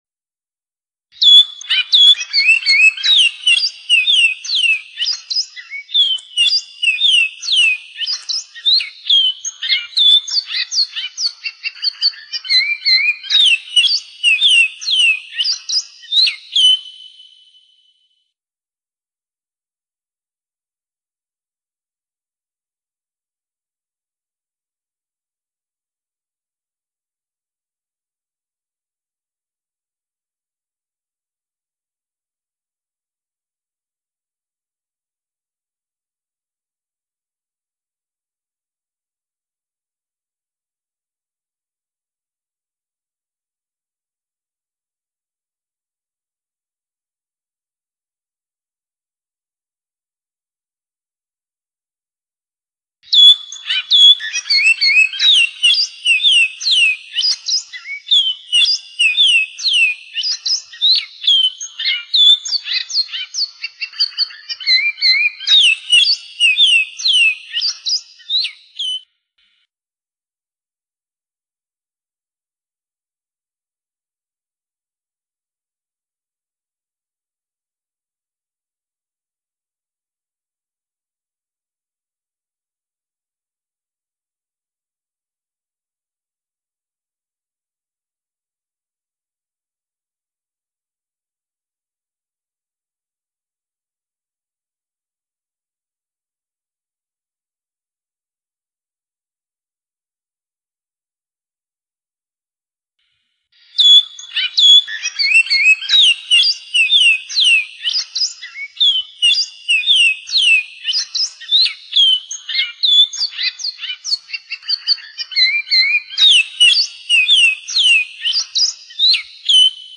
Cantos claros de la region central de venezuela, Bejuma , miranda, san felipe.